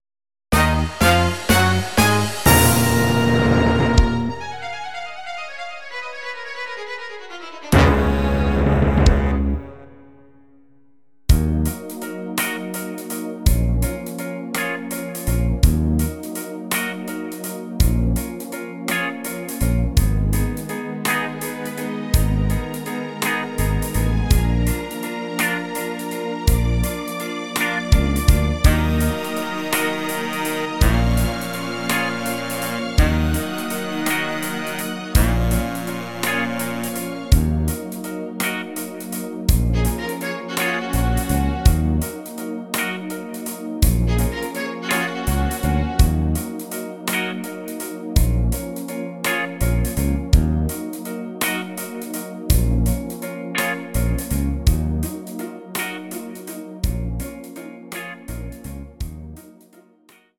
Rhythmus  Ballade